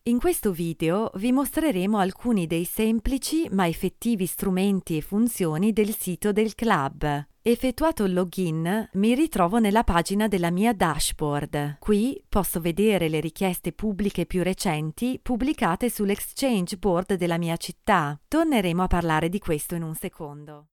Female; 20s/30s, teenager - sunny versatile voice over - modern, fresh and lively, sexy and mellow, native Italian speaker. Standard Italian accent.
Sprecherin italienisch, Muttersprachlerin.
Sprechprobe: Sonstiges (Muttersprache):